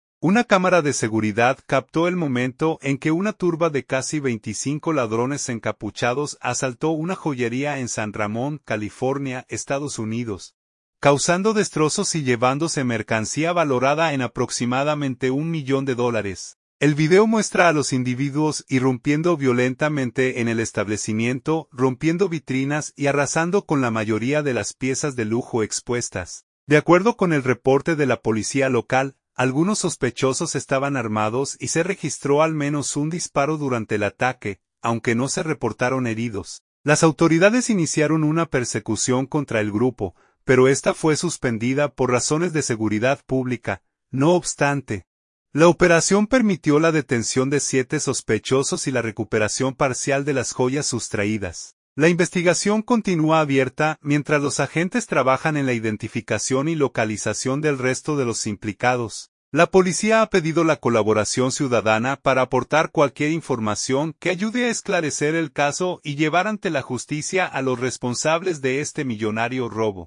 Una cámara de seguridad captó el momento en que una turba de casi 25 ladrones encapuchados asaltó una joyería en San Ramón, California, Estados Unidos, causando destrozos y llevándose mercancía valorada en aproximadamente un millón de dólares.